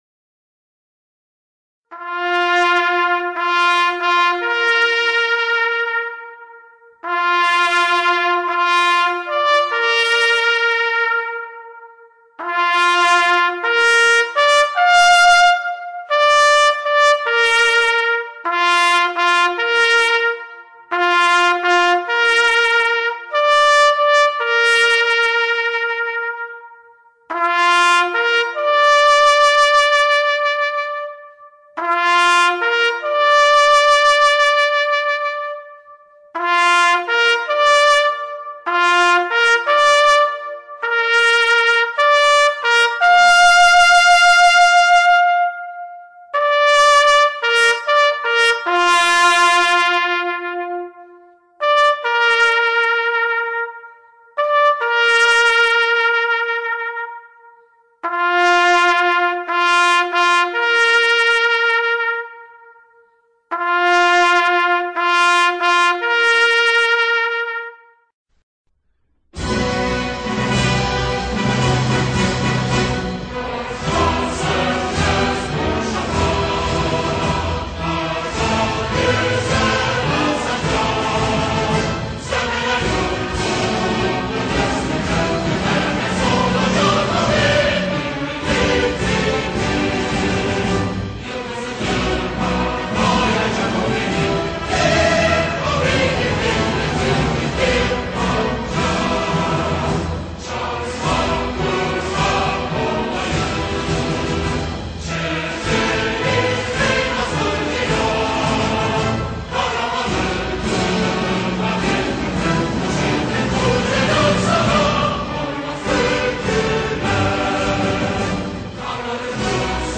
"Ti" Sesi ile (1 Dakikalık)
İstiklal Marşı'nın başında 1 dakikalık "ti" sesi ile başlayan versiyonudur.